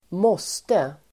Uttal: [²m'å:ste]